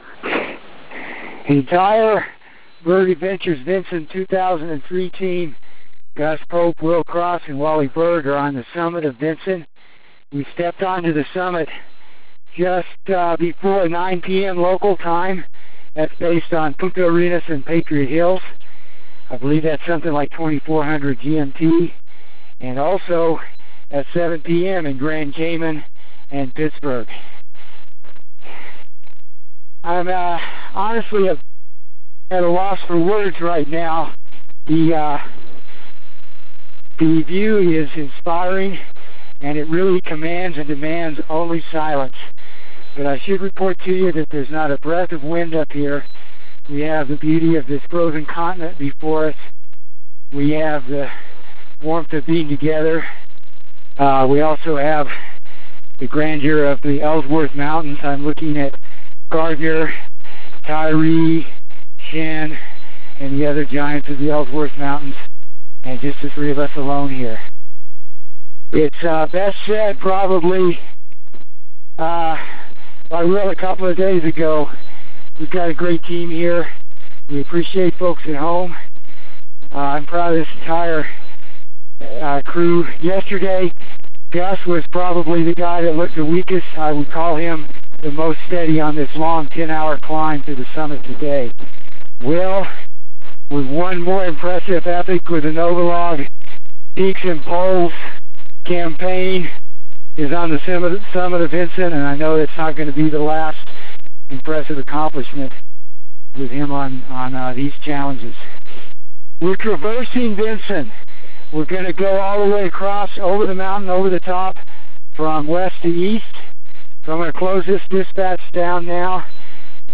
December 16 – Team reports from summit of Vinson!